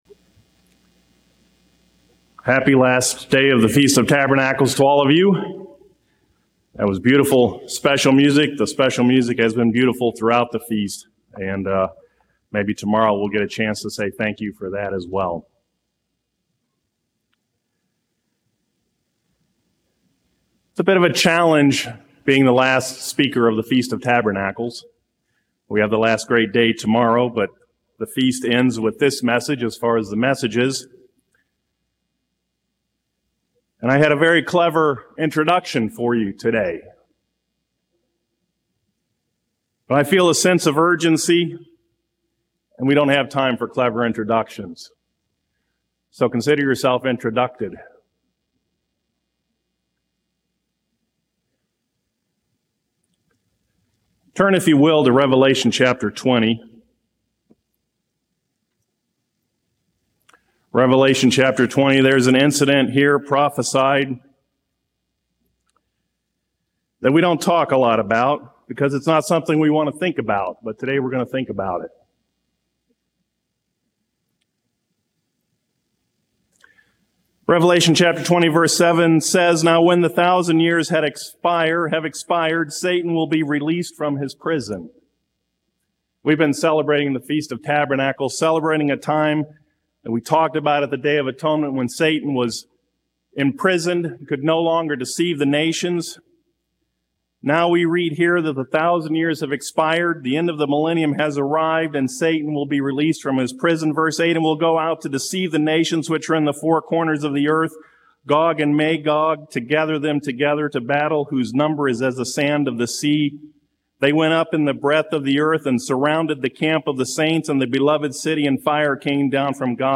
This sermon was given at the Gatlinburg, Tennessee 2023 Feast site.